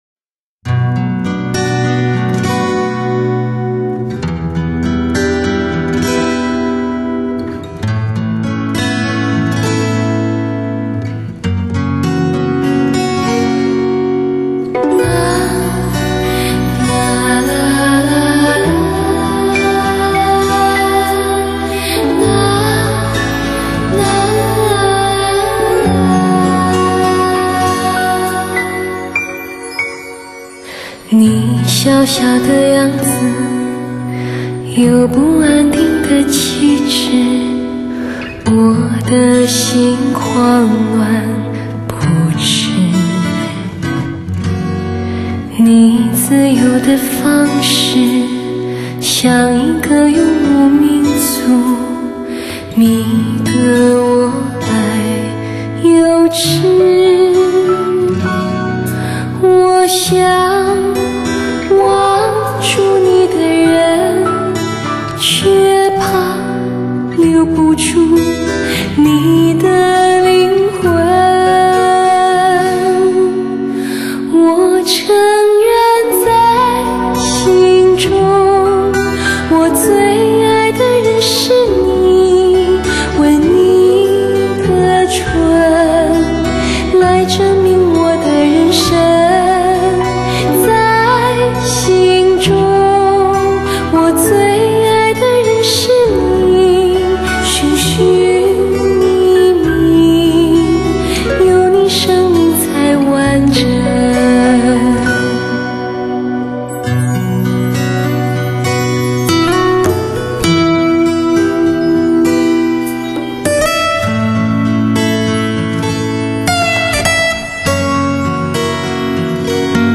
超班制作 细致逼真 质感惊人